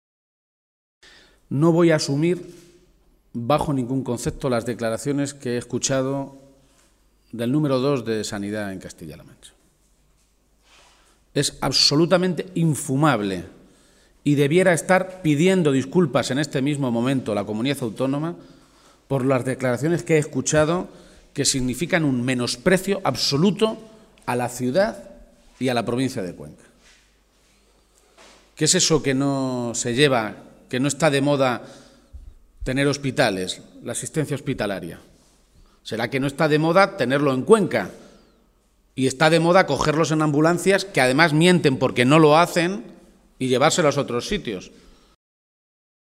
El líder de los socialistas castellano-manchegos hacía estas manifestaciones en la capital conquense, donde denunció además que “Cospedal se ceba particularmente con Cuenca” a través de su política de recortes en sanidad, educación y servicios sociales.